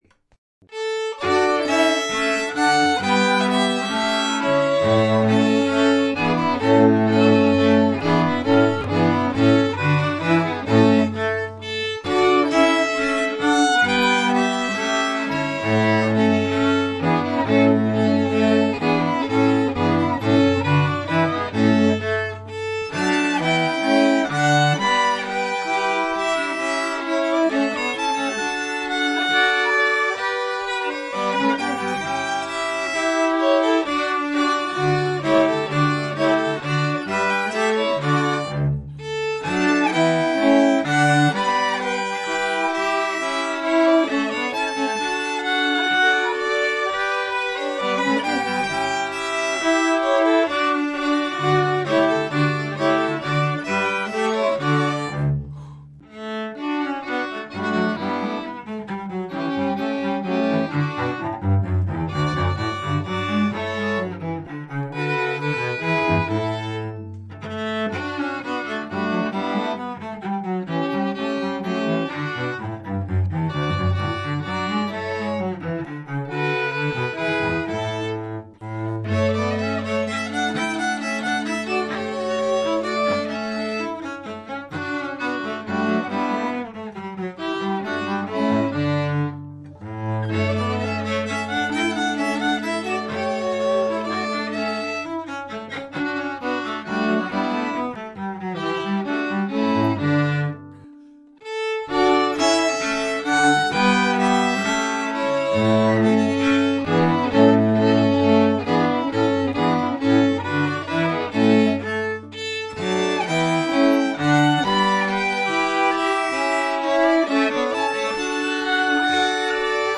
Here also are three examples of multi-track recording, with me playing all the instruments.
Corelli, and a minuet and trio from
Haydn's quartet Op 20 No 4.